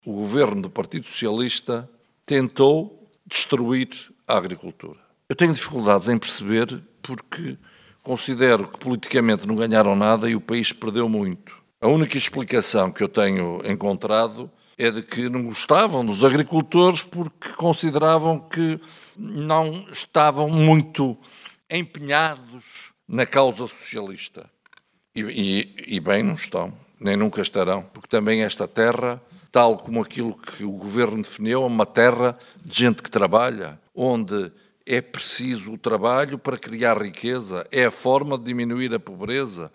José Manuel Fernandes, esteve em Mirandela, na apresentação da candidatura da AD à Câmara Municipal.